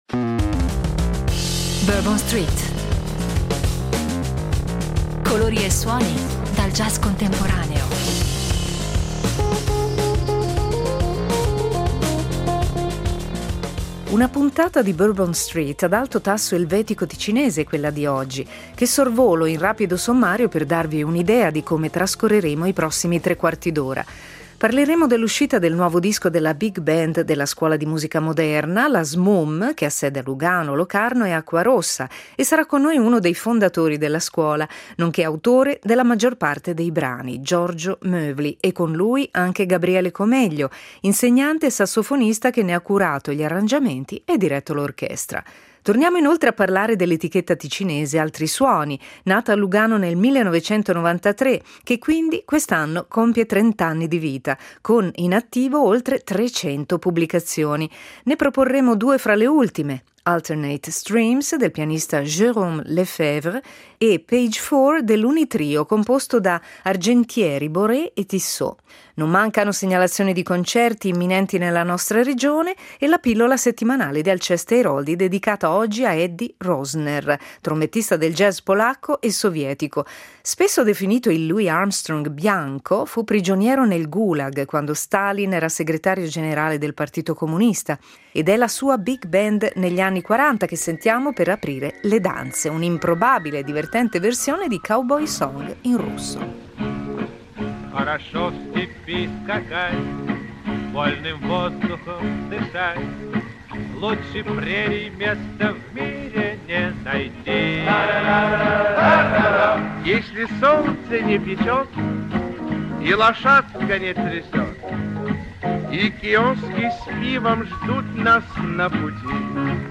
Vecchio e nuovo insieme, spinta verso il futuro coniugata con il più totale rispetto della propria storia: colori e suoni dal jazz contemporaneo insomma.